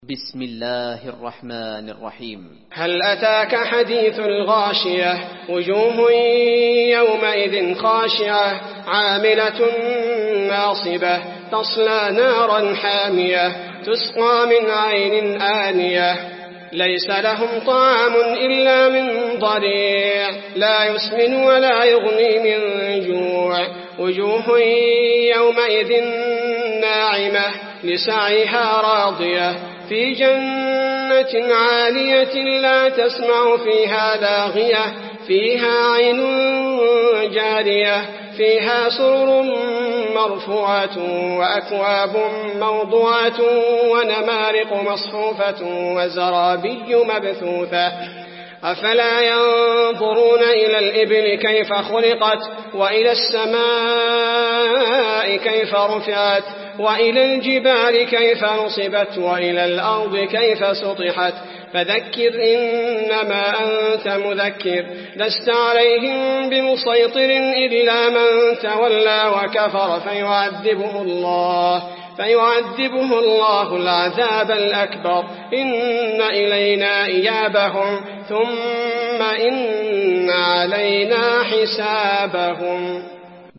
المكان: المسجد النبوي الغاشية